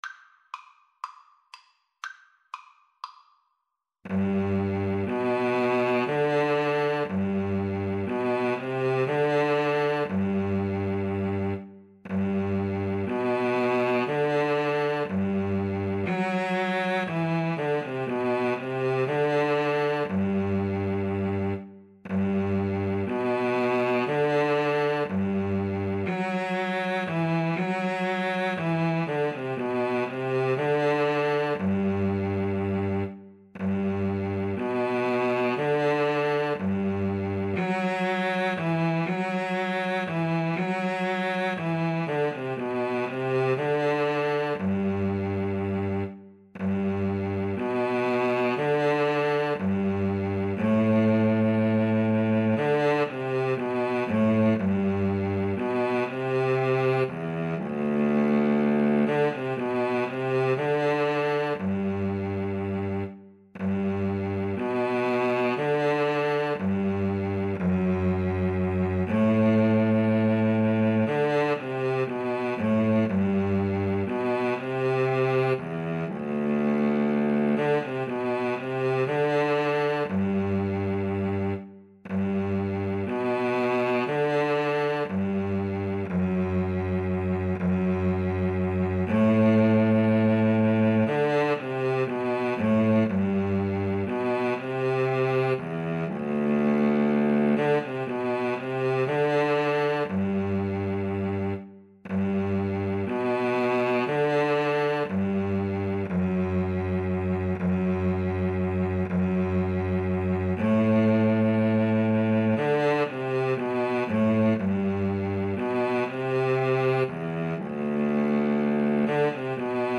Free Sheet music for Viola-Cello Duet
G major (Sounding Pitch) (View more G major Music for Viola-Cello Duet )
Allegro (View more music marked Allegro)
4/4 (View more 4/4 Music)
Traditional (View more Traditional Viola-Cello Duet Music)